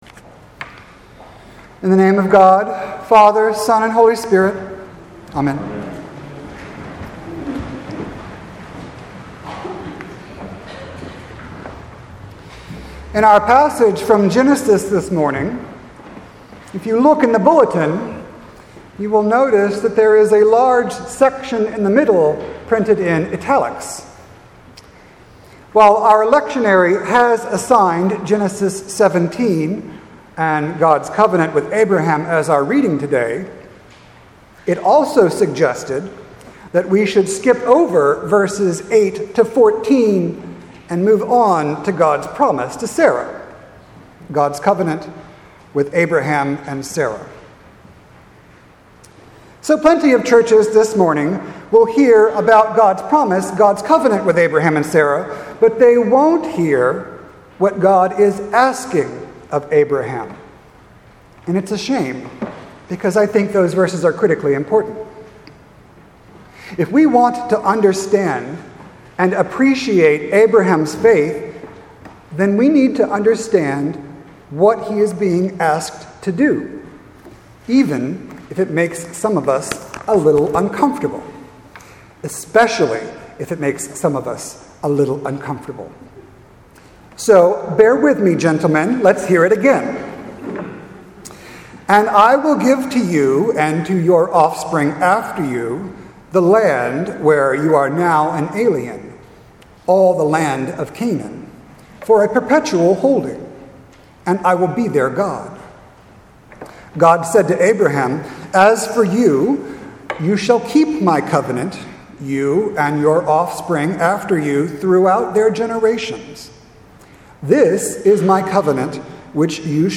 sermon-2-25-18.mp3